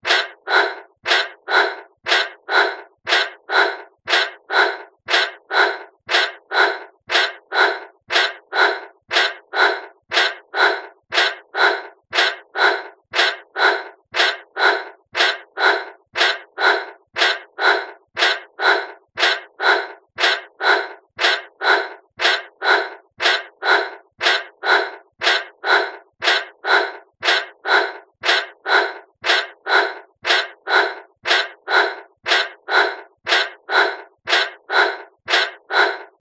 女性の息切れ 着信音
激しい運動をした時の女性の息切れ「スーハースーハー」。呼吸の音。